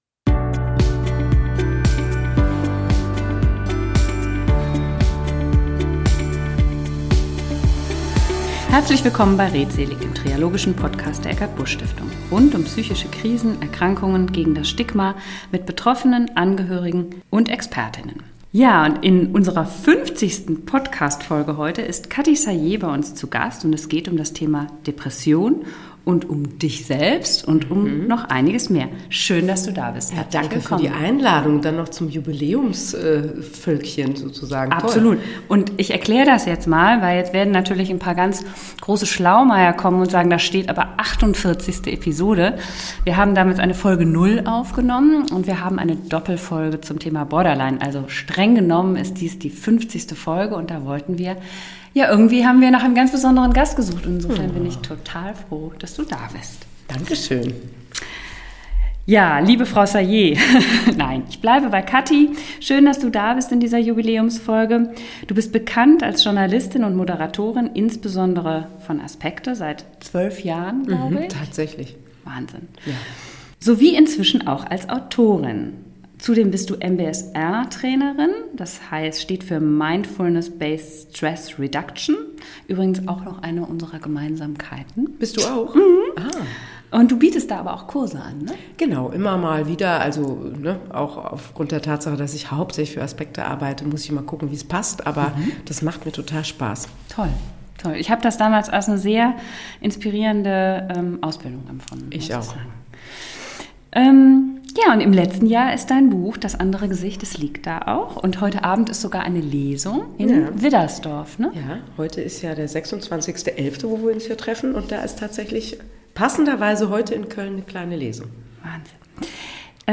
Folge 48: Jubiläumsfolge mit Katty Salié - Das andere Gesicht: Ein Gespräch über Depressionen ~ Redseelig Podcast
In der 50. Jubiläumsfolge von Redseelig ist Katty Salié (Journalistin, Moderatorin und Autorin) zu Gast bei Redseelig.